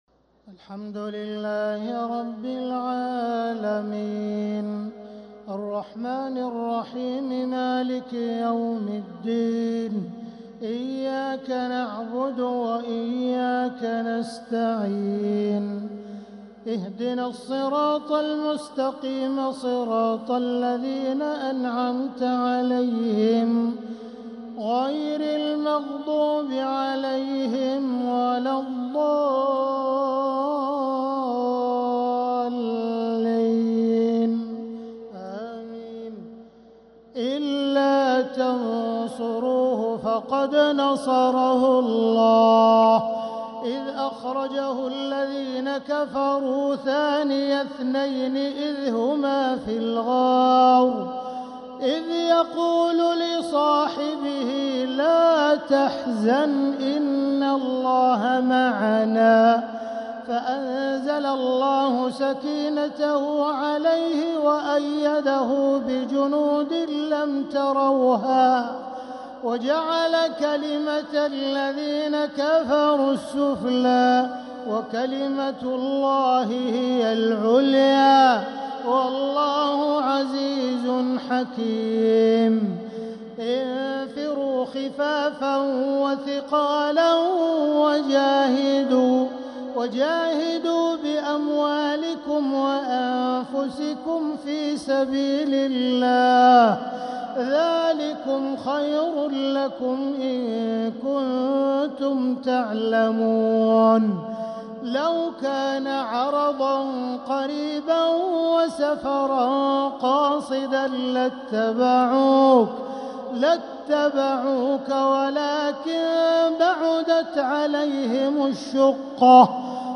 تراويح ليلة 13 رمضان 1446هـ من سورة التوبة (40-60) | taraweeh 13th niqht Surah At-Tawba 1446H > تراويح الحرم المكي عام 1446 🕋 > التراويح - تلاوات الحرمين